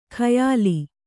♪ khayāli